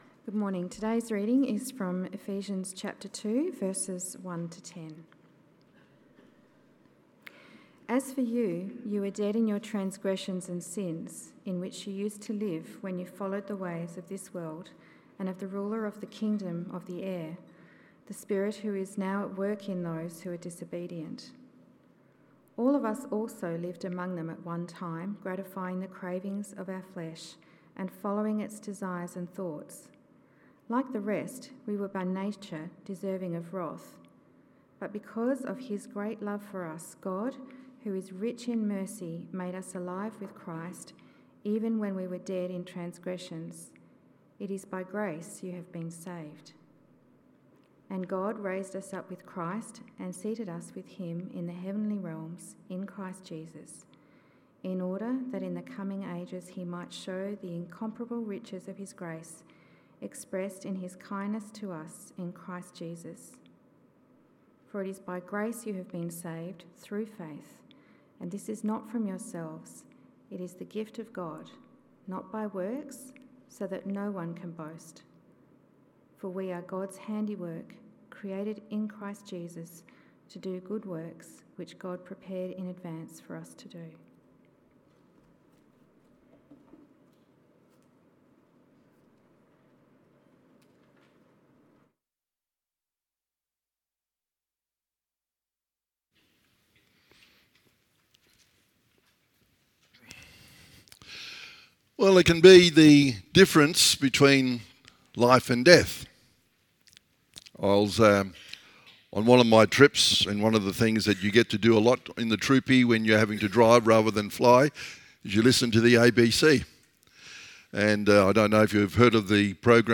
Sermons by Willetton Christian Church